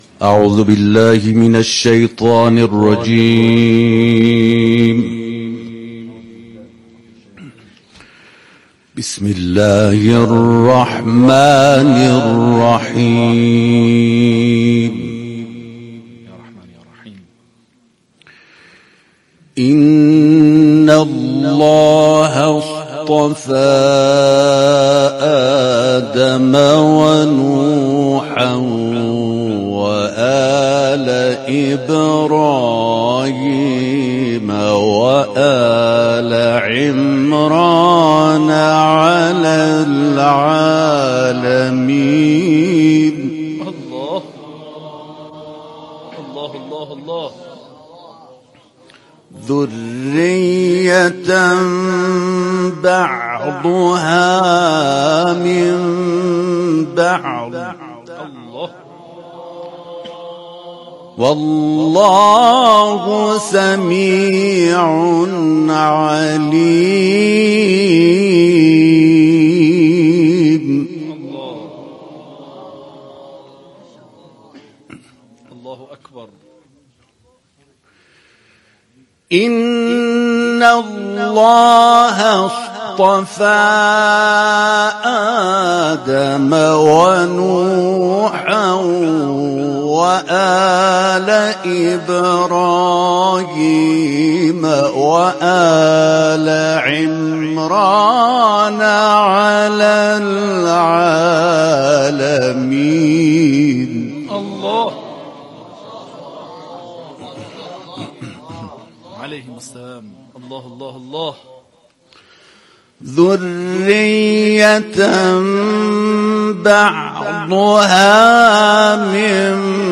صوت/ طنین صوت قرآن در منزل شهید حاجی‌حسنی‌کارگر
گروه چندرسانه‌ای ــ محفل انس با قرآن بیستمین روز از سفر کاروان قرآنی انقلاب در منزل شهید قرآنی منا محسن حاجی حسنی کارگر در مشهد مقدس برگزار شد.